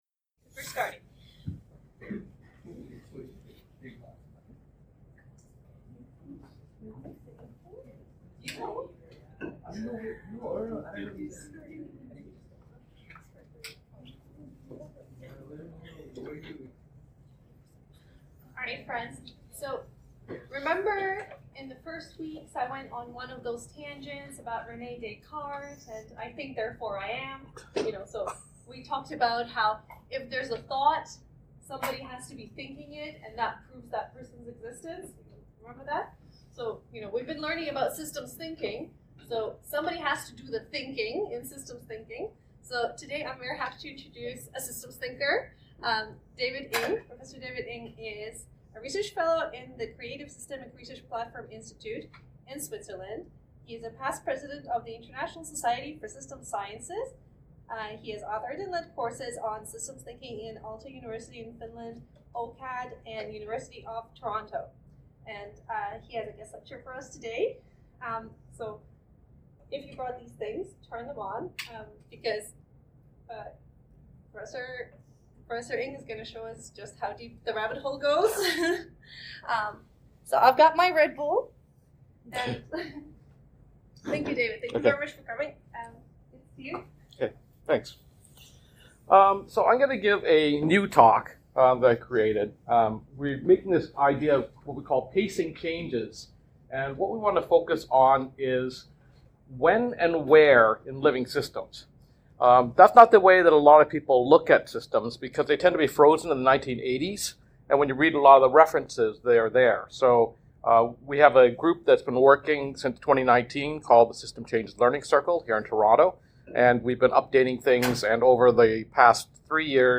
After short self-introductions by participants, the panelists were lightly guided through some focus questions. How does a music city relate to urban communities?
What systems are associated with a music city? Between focus questions, participants were invited to offer reflections and insights.
A standalone audio was also created during the meeting.